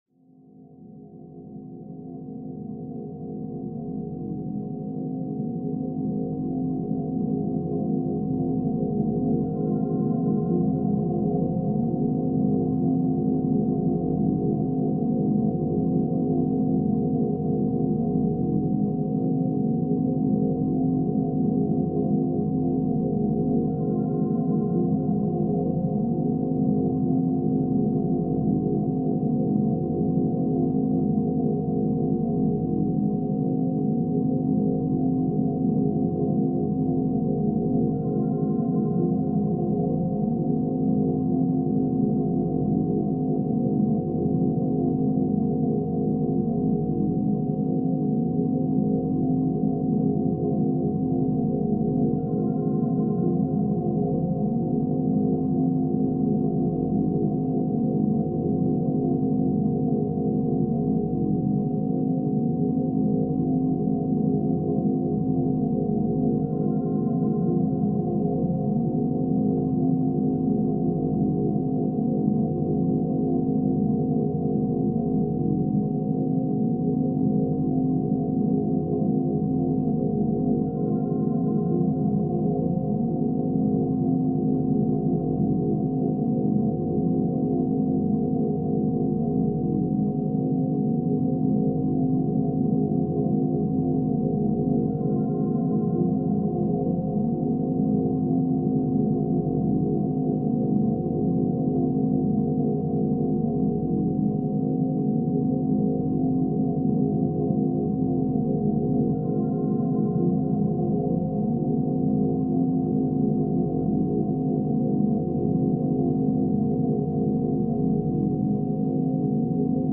Flow State Activation – Monaural Beats for Creativity and Focus